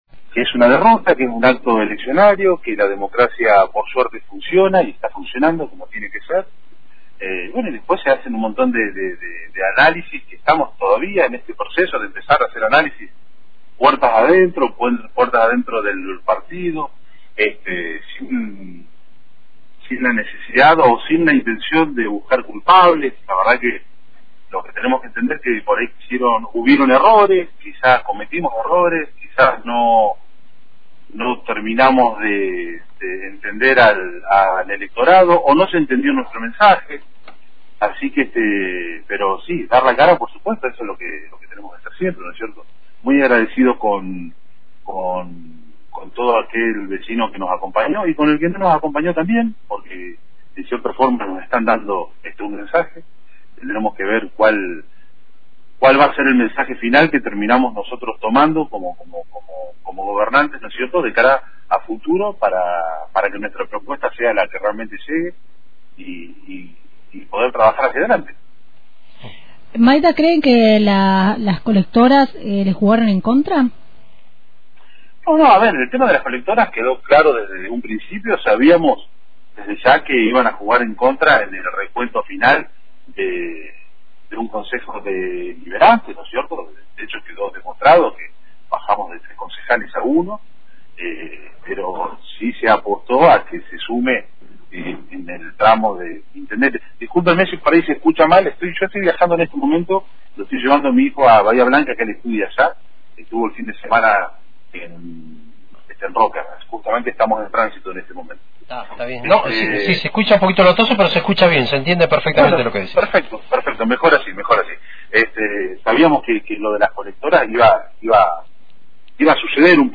Dialogamos con Gustavo Maida, presidente del Bloque Concejales por JSRN, quien brindó detalles de la lectura que hacen del resultado electoral.